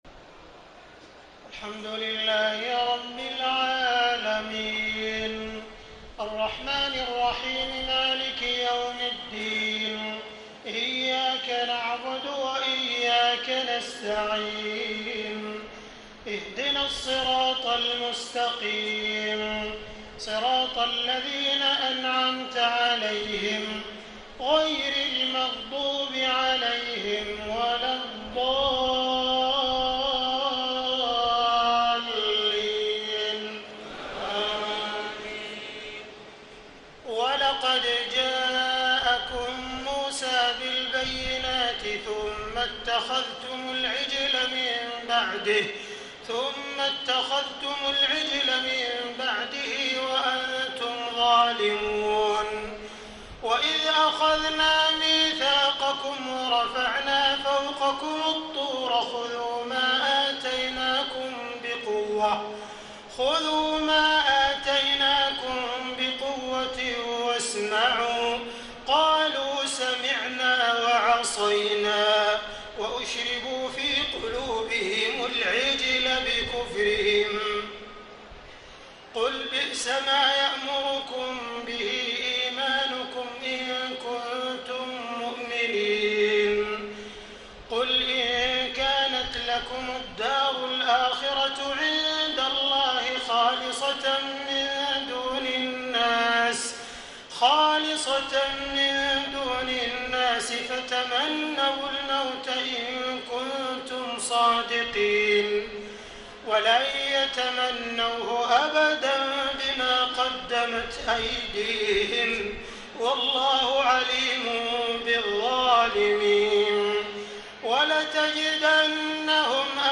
تهجد ليلة 21 رمضان 1434هـ من سورة البقرة (92-141) Tahajjud 21 st night Ramadan 1434H from Surah Al-Baqara > تراويح الحرم المكي عام 1434 🕋 > التراويح - تلاوات الحرمين